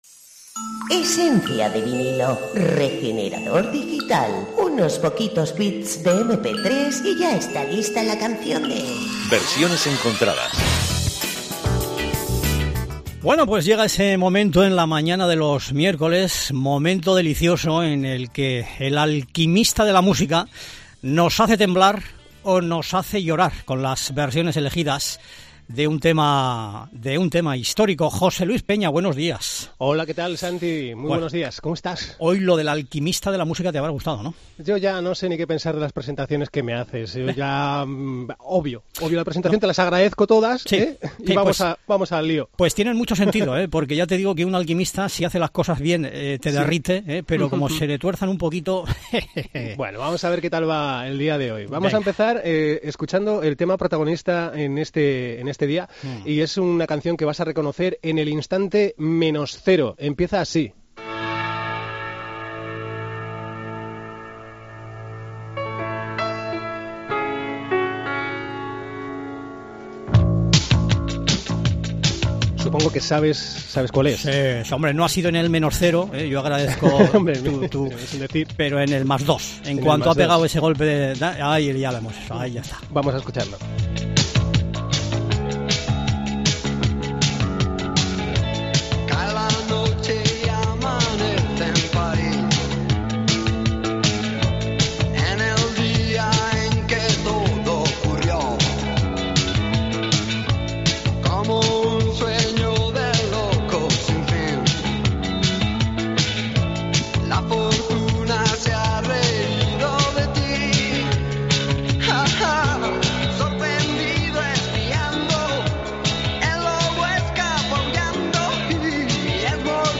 LA UNIÓN: Entrevista sobre “Lobo Hombre en París”